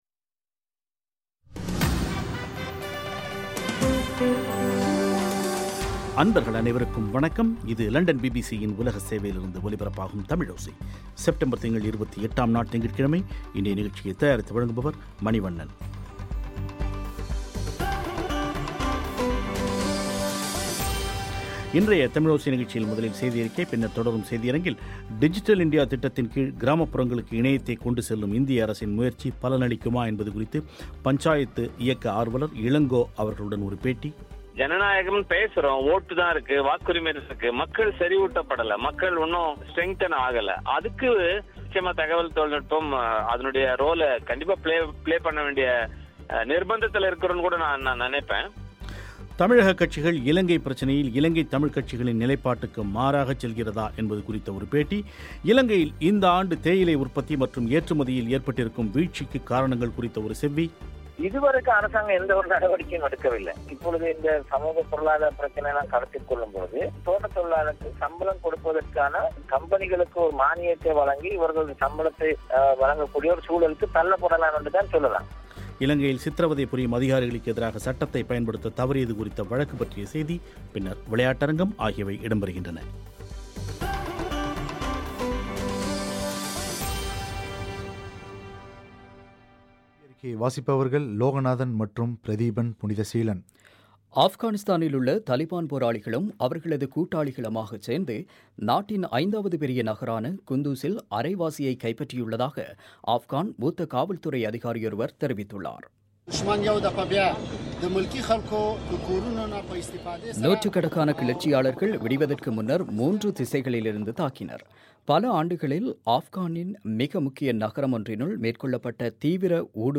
தமிழ்நாடு இலங்கைப் பிரச்சனையில் இலங்கைத் தமிழ்க் கட்சிகளின் நிலைப்பாட்டுக்கு மாறாகச் செல்கிறதா என்பது குறித்து ஒரு பேட்டி